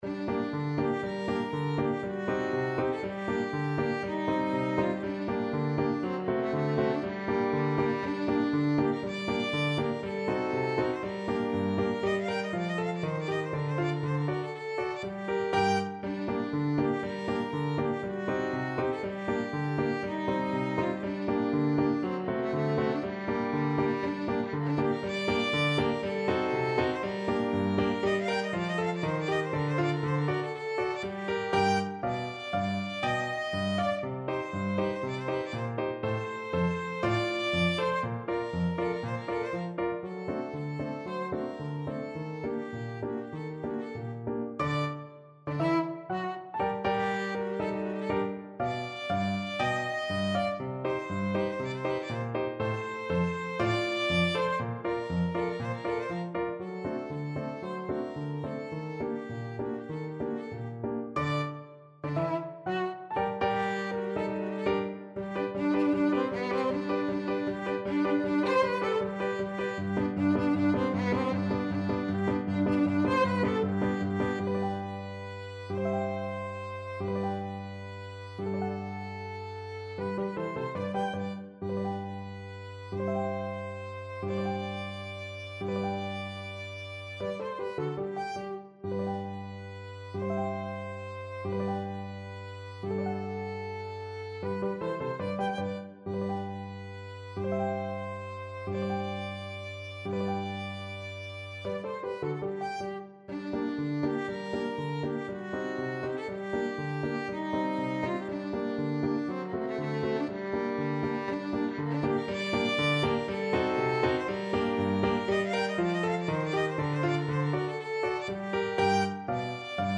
2/4 (View more 2/4 Music)
Allegro = 120 (View more music marked Allegro)
Classical (View more Classical Violin Music)